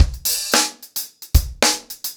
DaveAndMe-110BPM.35.wav